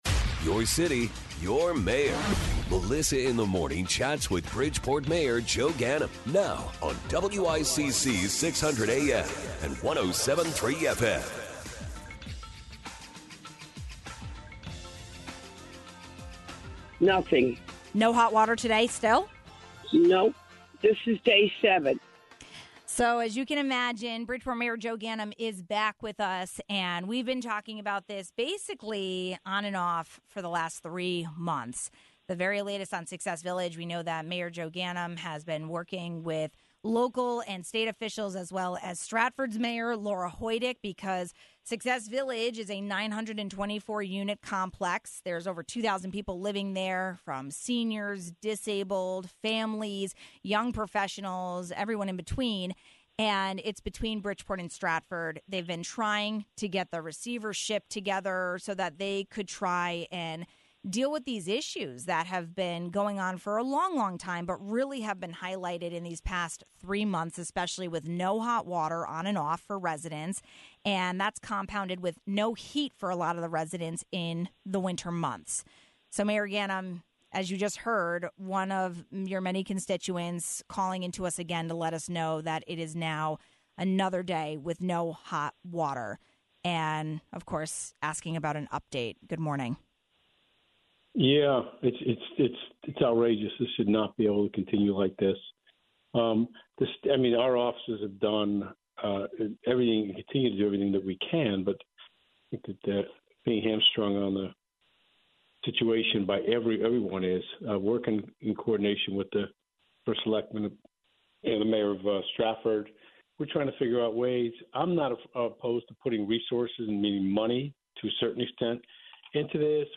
While there are a lot of things happening and news headlines coming out of the Park City, most of our calls from Bridgeport residents are about Success Village and the Board of Education. We talked about them both with Bridgeport Mayor Joe Ganim.